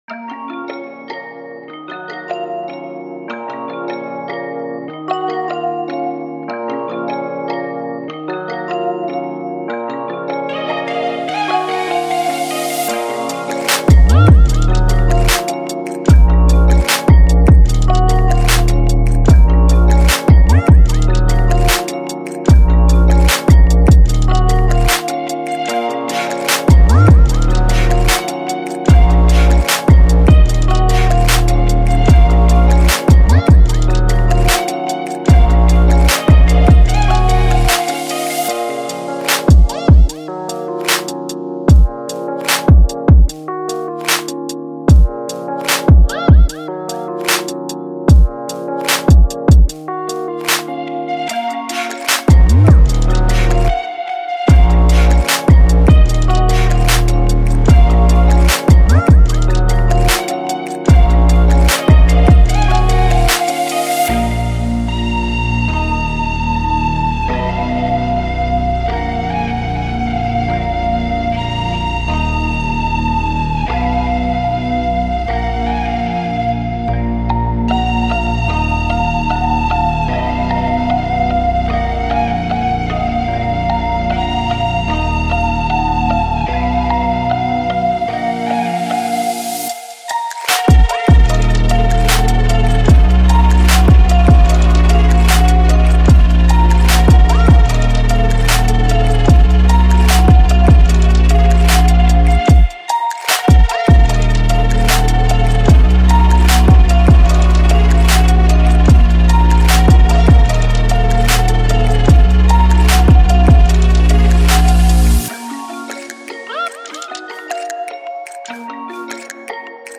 دانلود اهنگ بیس دار قوی خارجی معروف بی کلام